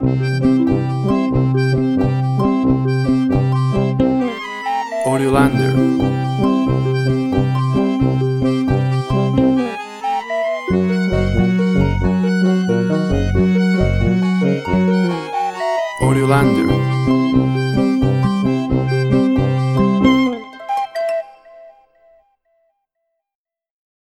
Tempo (BPM): 88